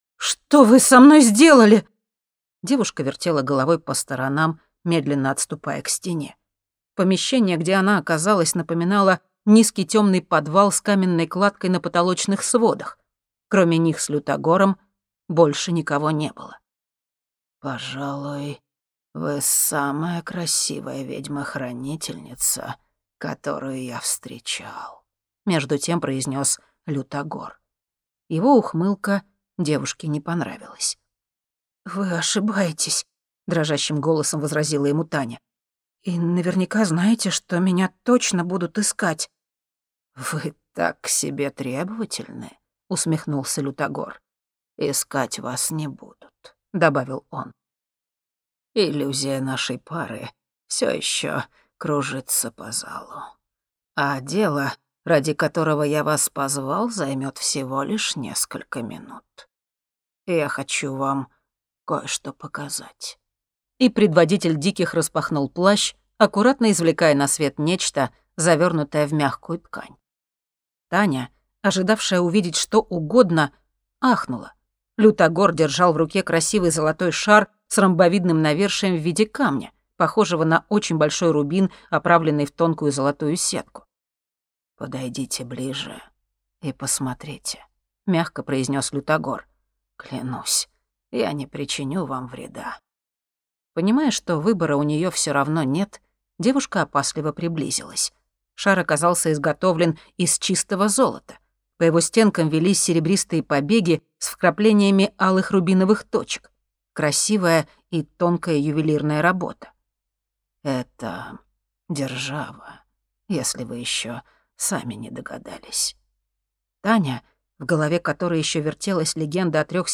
Аудиокнига Чародол. Чародольский браслет | Библиотека аудиокниг
Прослушать и бесплатно скачать фрагмент аудиокниги